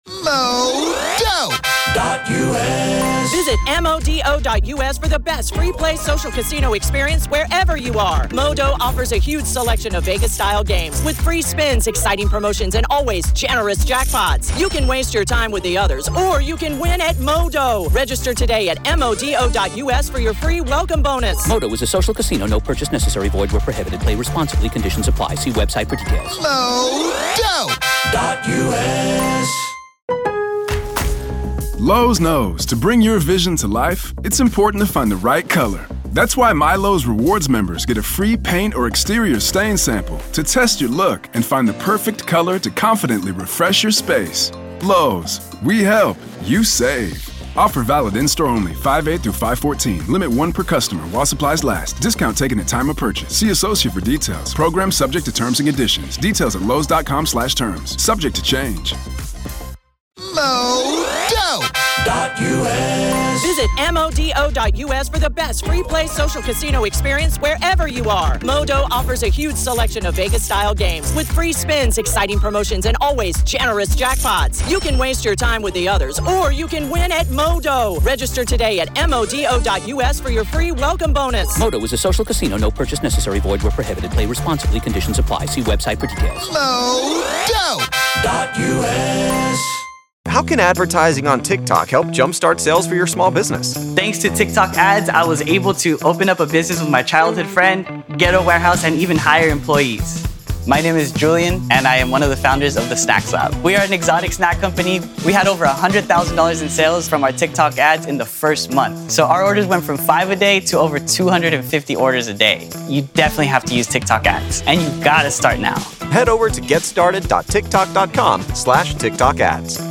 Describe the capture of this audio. This is audio from the courtroom